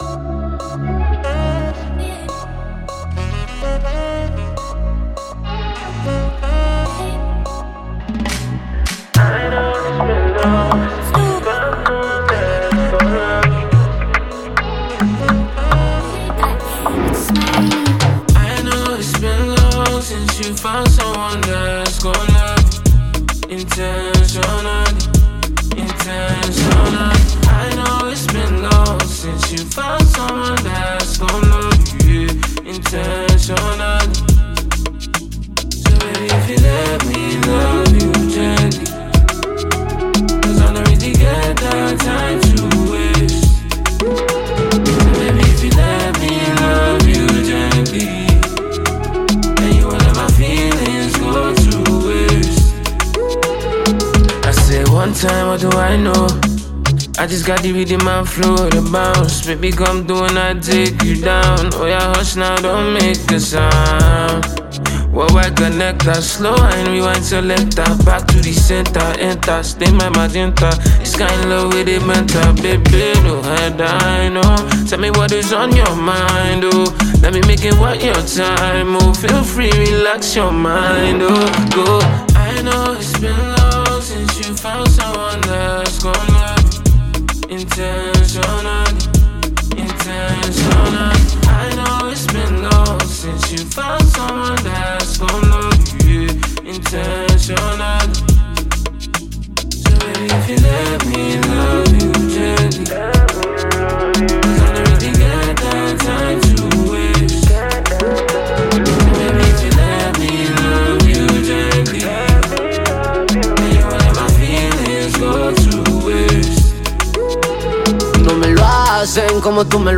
hip-hop
added a sultry verse on the track